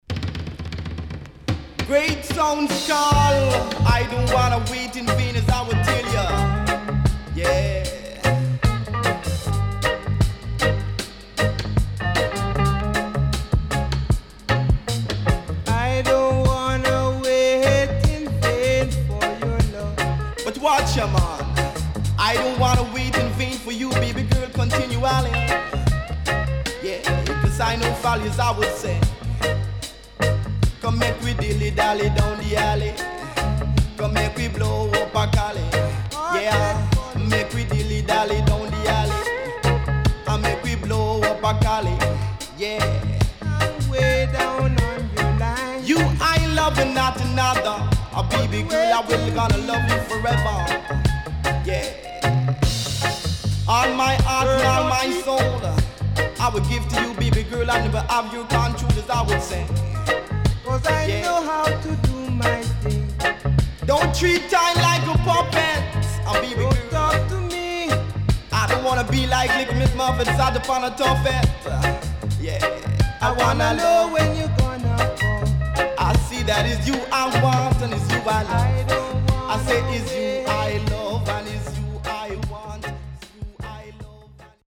HOME > Back Order [VINTAGE 7inch]  >  SWEET REGGAE
Cover & Deejay Cut
SIDE A:少しノイズ入りますが良好です。